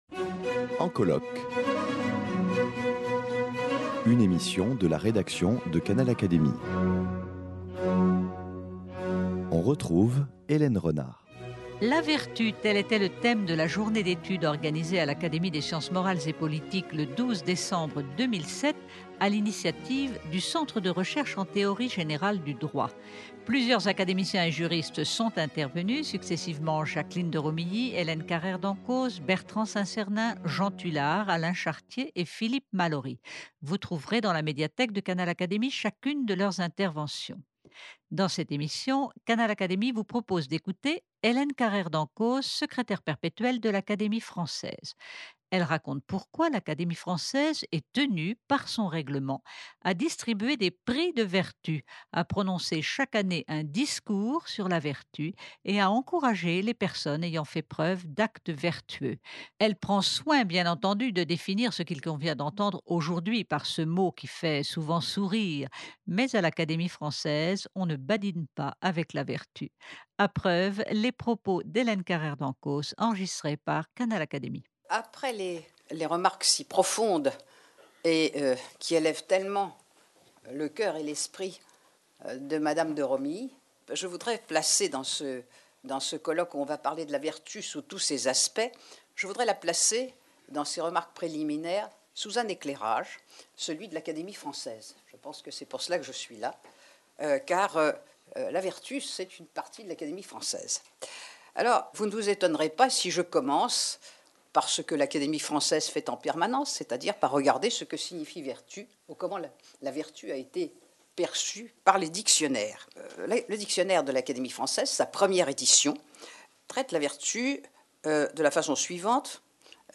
La vertu : tel était le thème de la journée d’étude organisée à l’Académie des sciences morales et politiques à l’initiative du Centre de Recherches en Théorie générale du Droit.
Voici l’intervention d’Hélène Carrère d’Encausse, Secrétaire perpétuel de l’Académie française qui raconte pourquoi et comment l’Académie est impliquée dans la défense de la vertu !